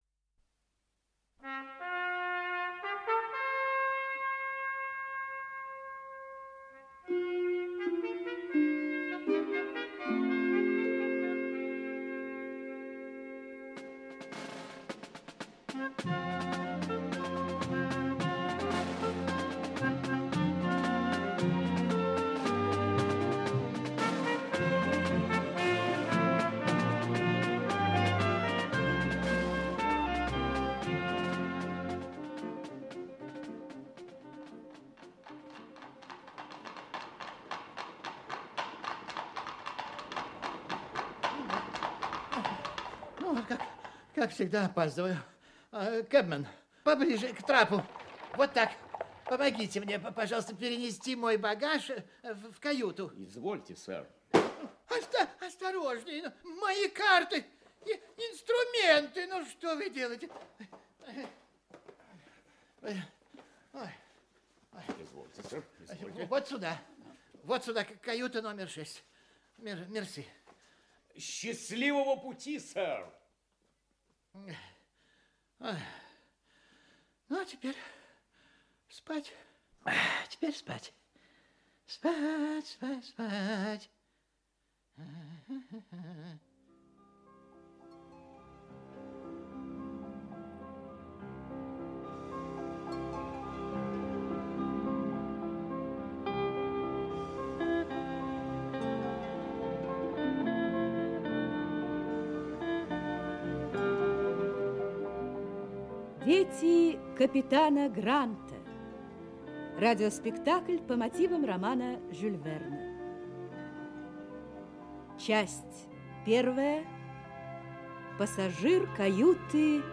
Аудиокнига Дети капитана Гранта (спектакль) | Библиотека аудиокниг
Aудиокнига Дети капитана Гранта (спектакль) Автор Жюль Верн Читает аудиокнигу Георгий Вицин.